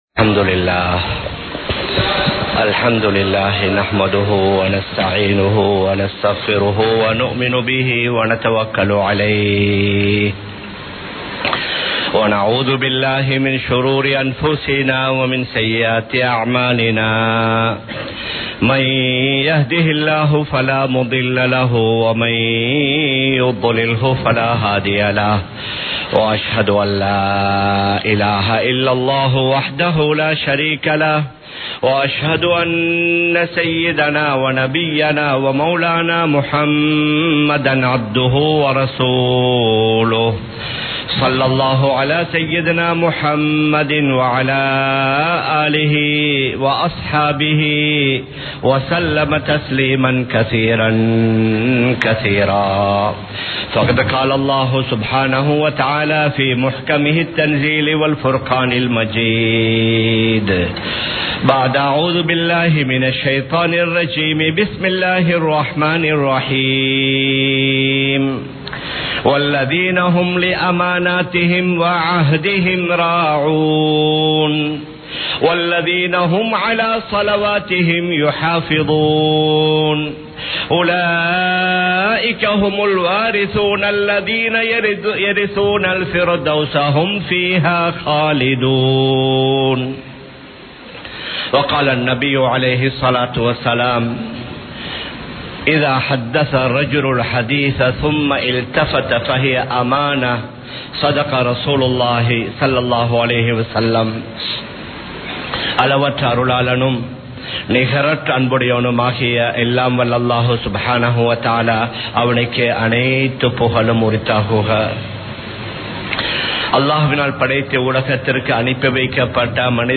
அமானிதம் பேணுவோம் | Audio Bayans | All Ceylon Muslim Youth Community | Addalaichenai
Kollupitty Jumua Masjith